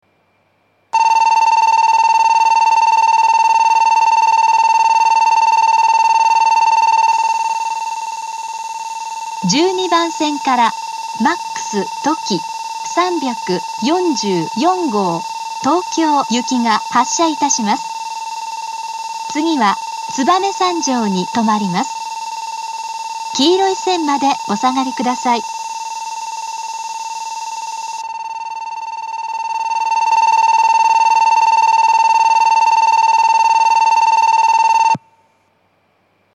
２面４線のホームで、全ホームで同じ発車ベルが流れます。
１２番線発車ベル Ｍａｘとき３４４号東京行の放送です。